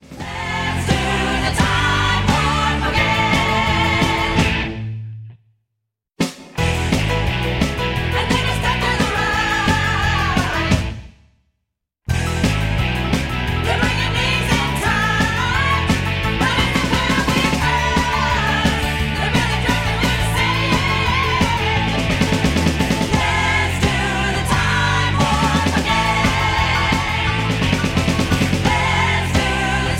MPEG 1 Layer 3 (Stereo)
Backing track Karaoke
Pop, Rock, Musical/Film/TV, 1970s